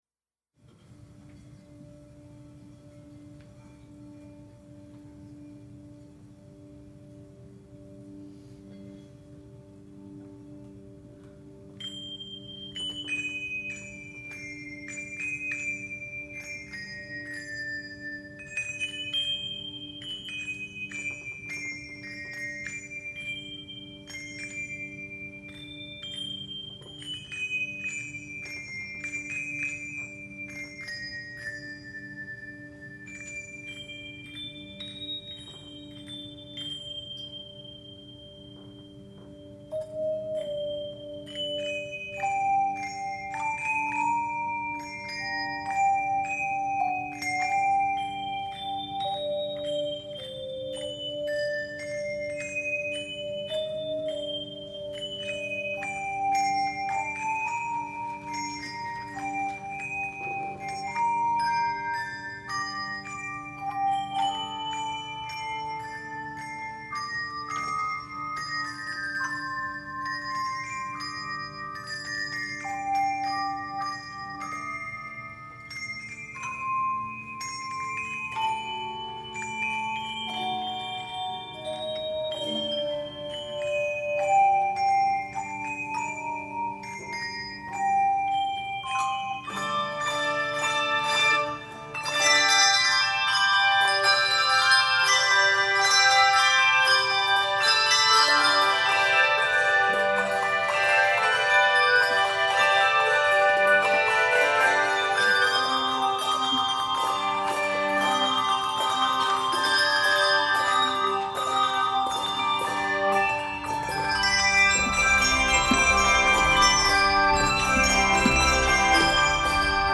Voicing: Handbells 5-7 Octave